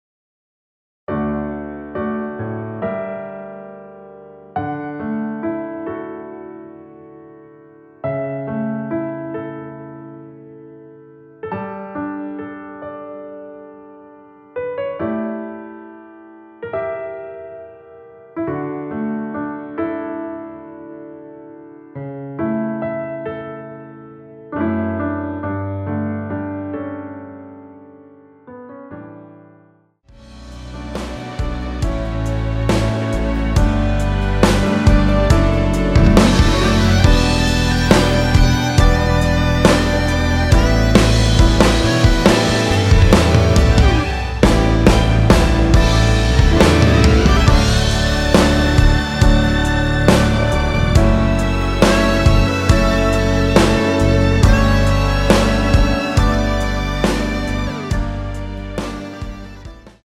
노래 들어가기 쉽게 전주 1마디 만들어 놓았습니다.(미리듣기 확인)
원키에서(-2)내린 MR입니다.
앞부분30초, 뒷부분30초씩 편집해서 올려 드리고 있습니다.